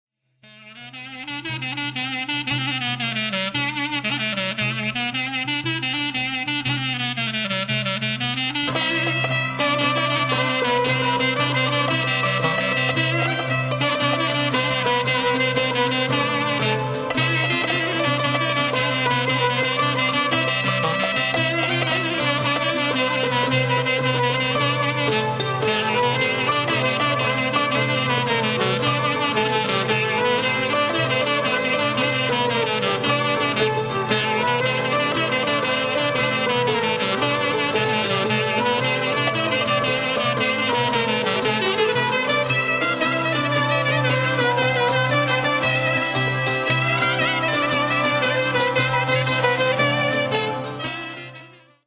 Zonaradikos from Thrace